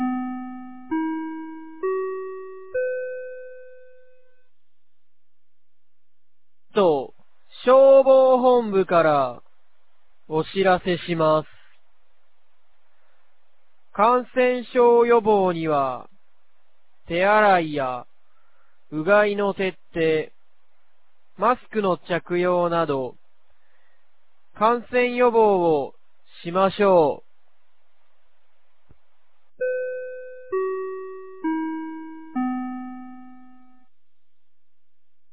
2025年01月27日 10時01分に、九度山町より全地区へ放送がありました。